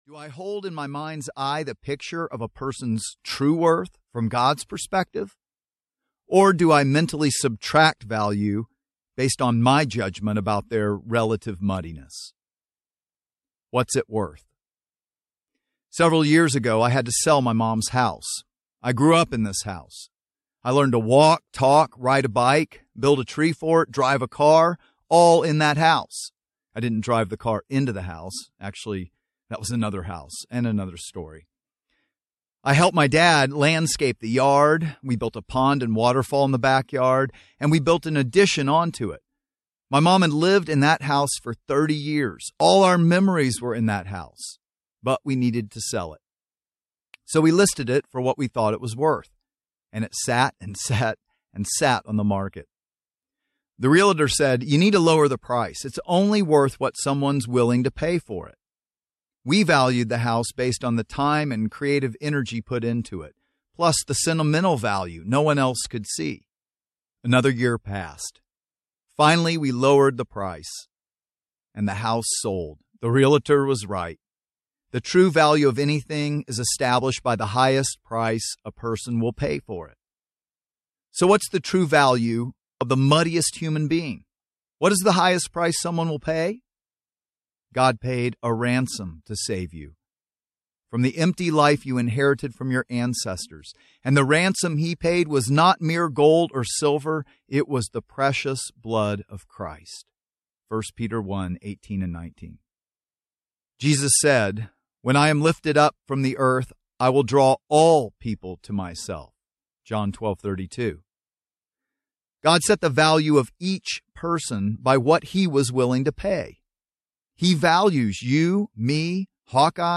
The Mud and the Masterpiece Audiobook
9.5 Hrs. – Unabridged